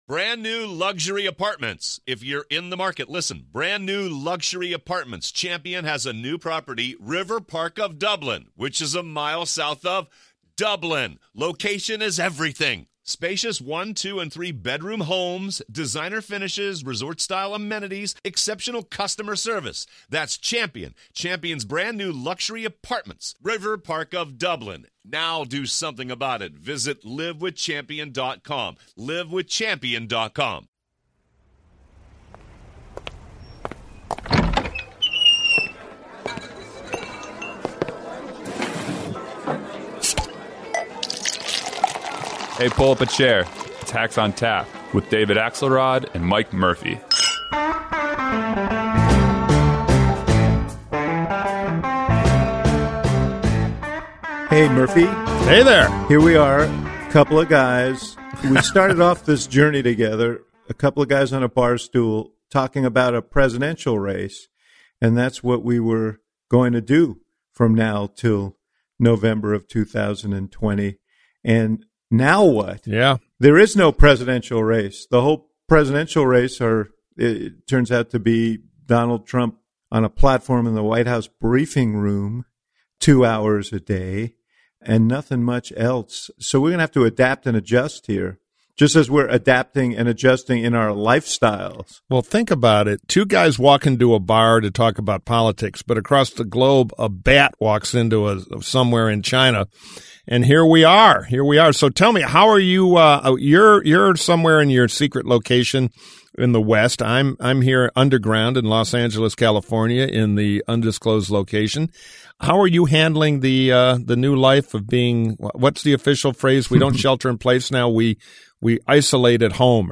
The socially-distanced Hacks reunite virtually to discuss the latest: